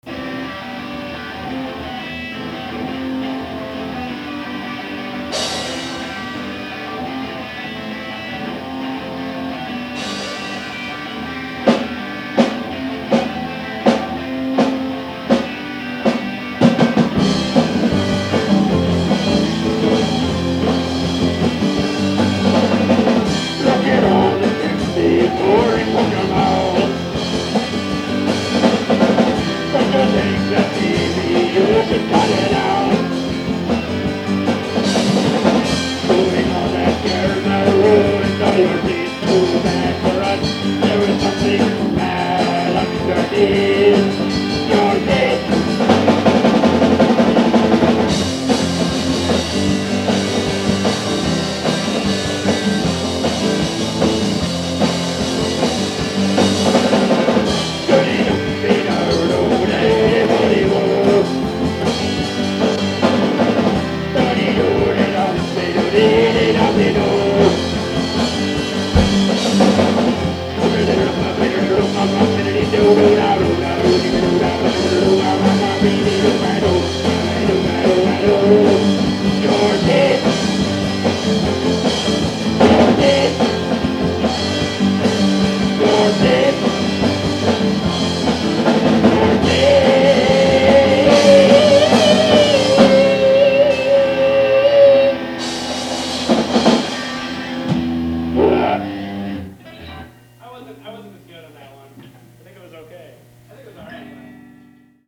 a deranged vocal performance